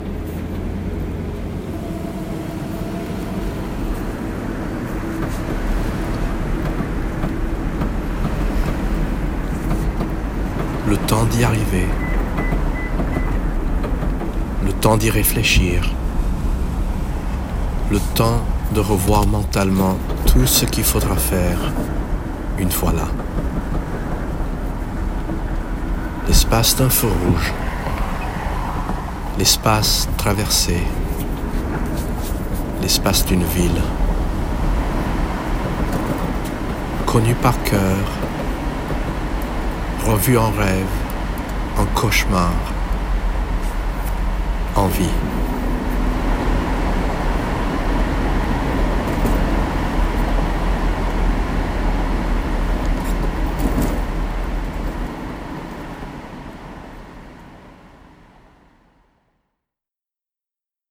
En voiture, sur la Pacific Coast Highway.